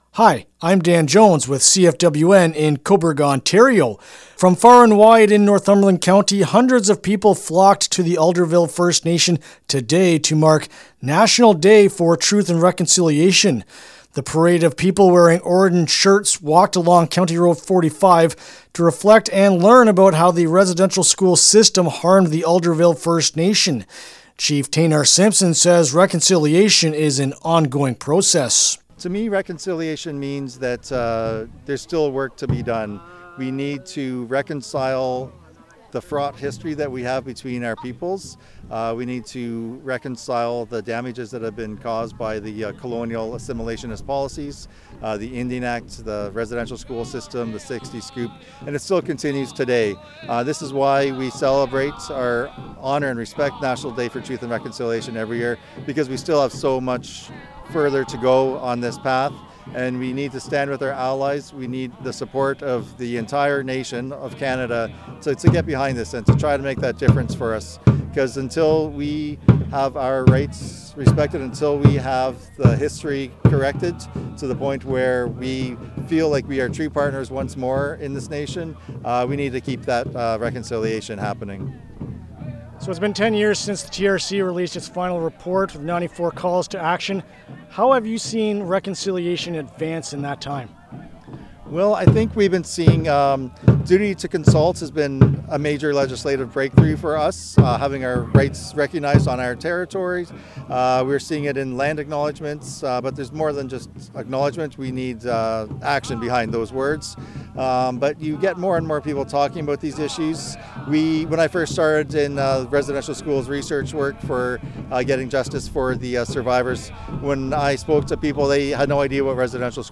During the walk, Chief Taynar Simpson would conduct interpretive stops, sharing local knowledge of the First Nations forced participation in either the Industrial or Day School program. He said the walk is reconciliation in action, but cautions more needs to be done.